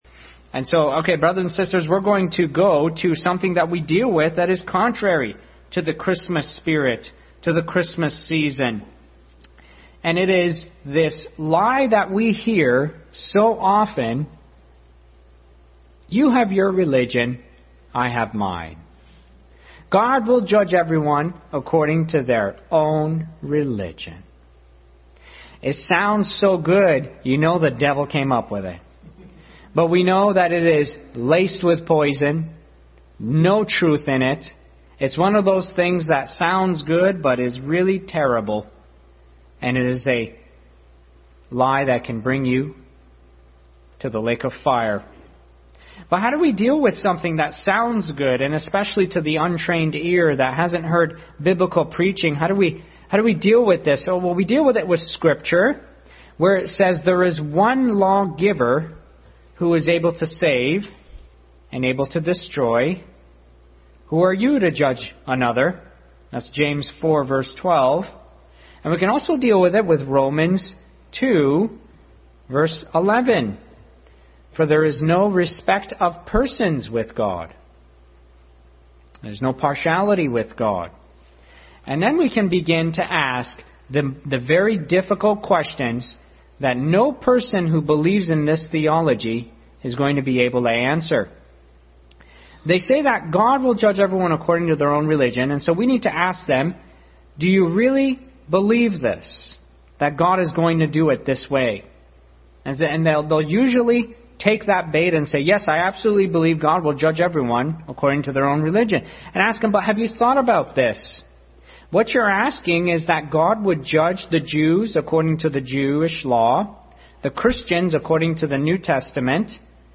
Family Bible Hour message.
Service Type: Family Bible Hour Family Bible Hour message.